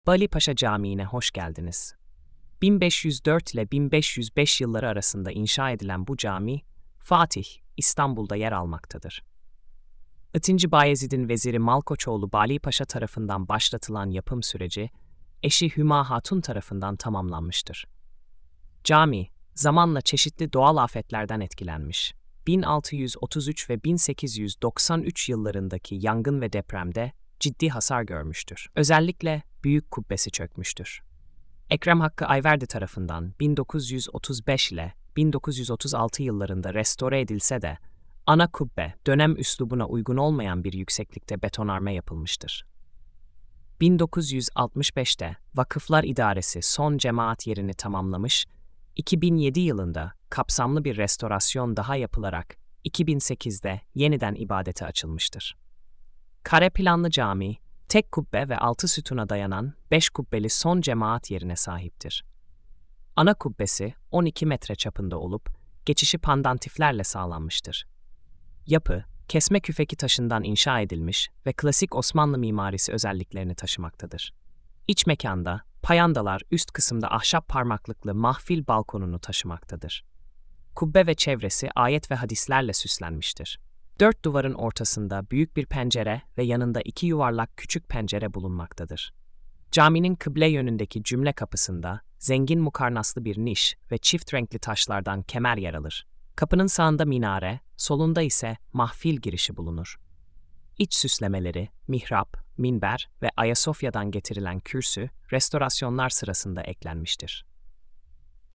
Sesli Anlatım